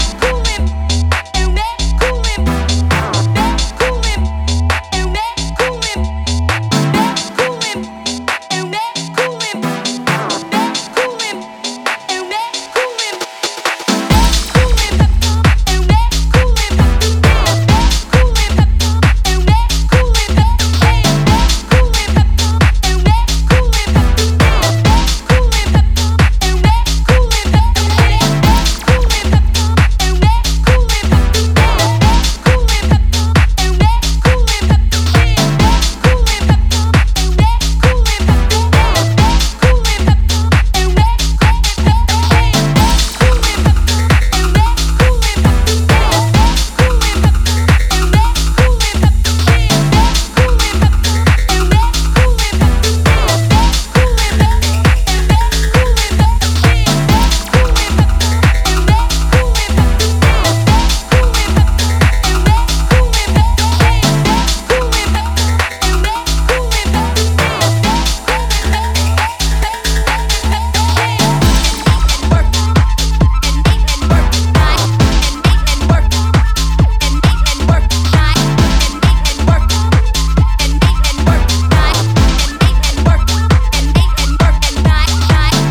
energetic and raw club-oriented EP